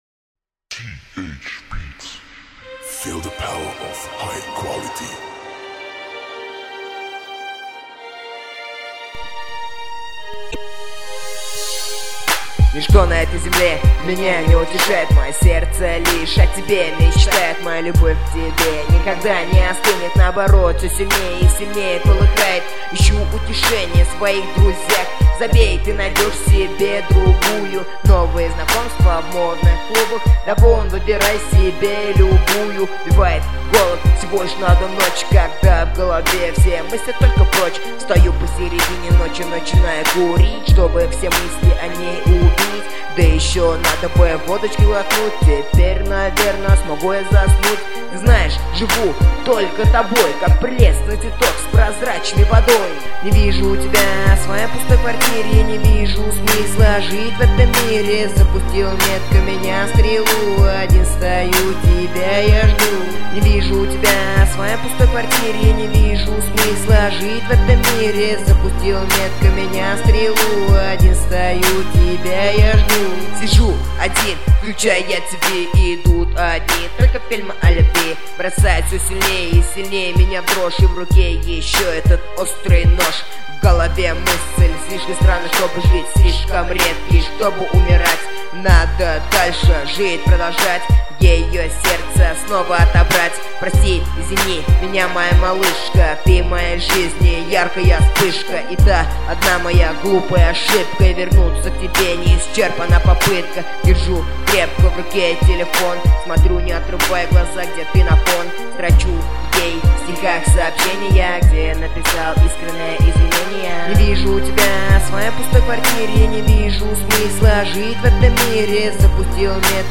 Жанр-рэп